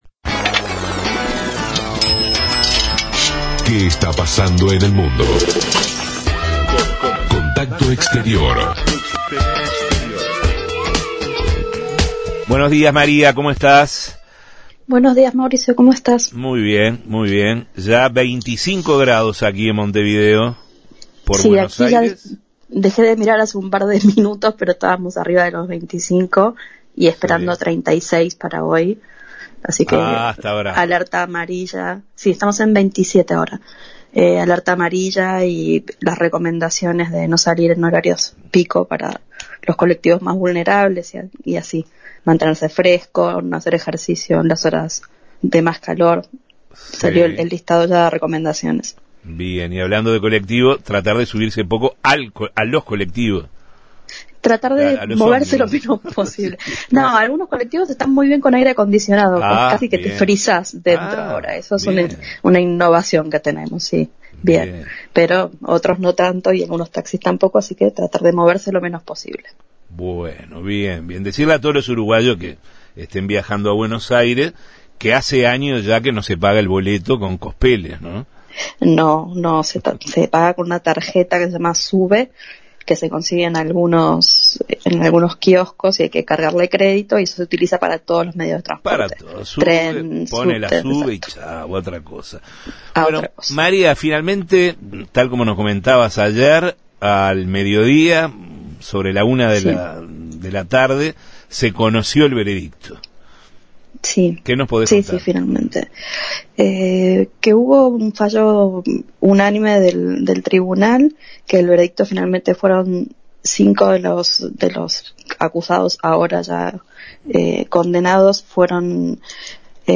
Corresponsal